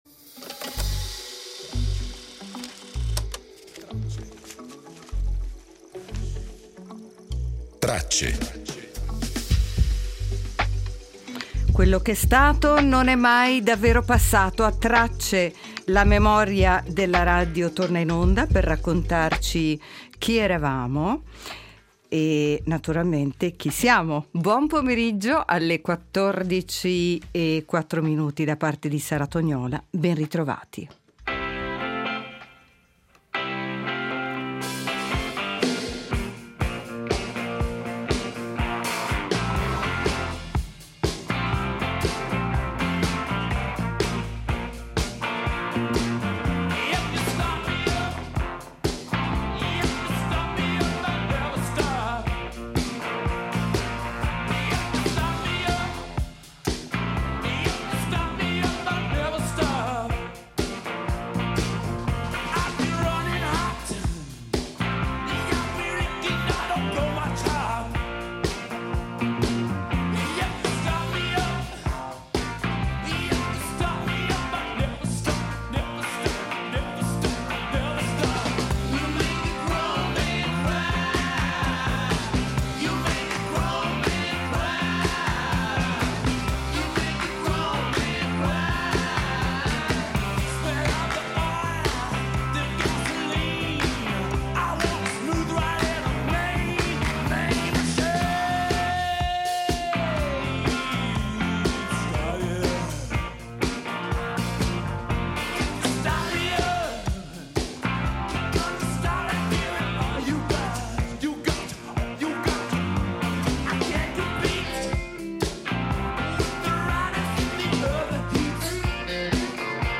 Estratti d’archivio sulla marcia bianca contro la pedofilia, sul millesimo gol di Pelè, sul compleanno di Angela Finocchiaro e sul matrimonio di Elisabetta e Filippo.